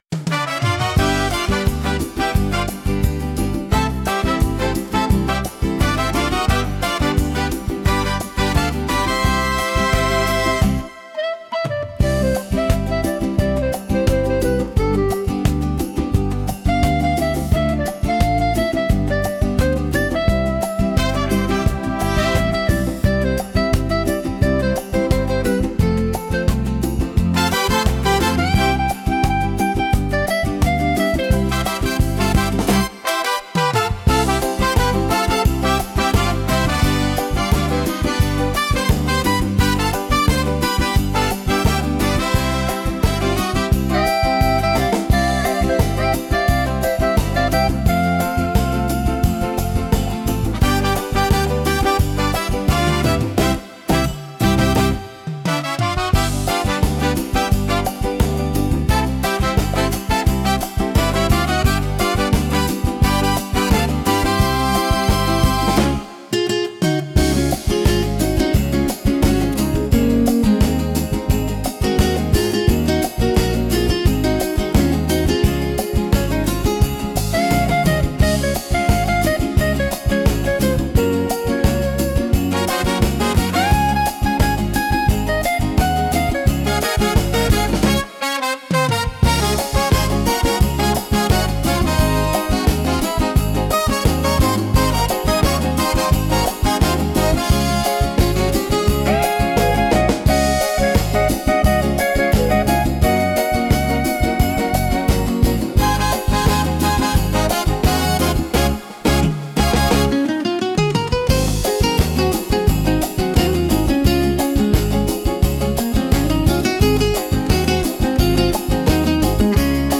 Ballo di gruppo
Traccia audio per DJ